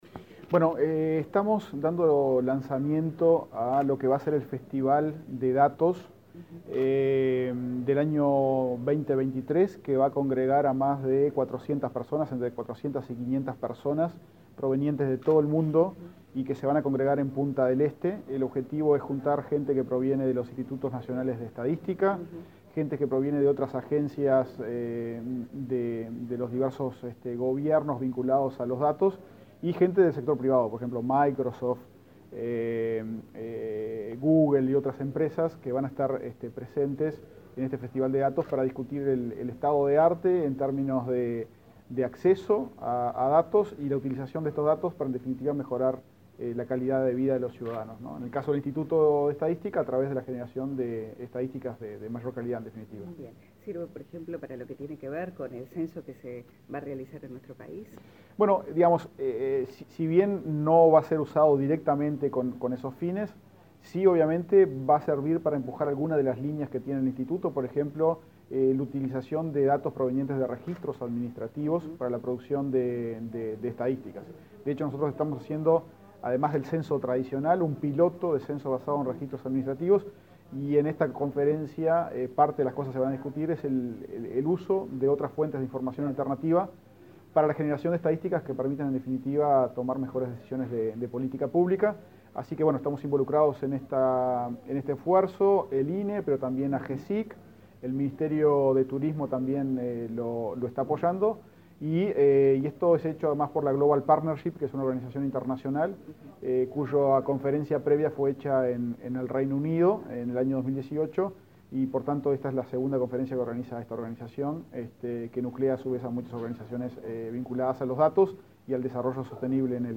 Declaraciones del director del INE, Diego Aboal
Declaraciones del director del INE, Diego Aboal 21/11/2022 Compartir Facebook X Copiar enlace WhatsApp LinkedIn El director del Instituto Nacional de Estadística (INE), Diego Aboal, dialogó con la prensa, luego de participar en el acto de lanzamiento del Festival de Datos 2023, que se llevará a cabo en Punta del Este entre el 7 el 9 de noviembre del próximo año.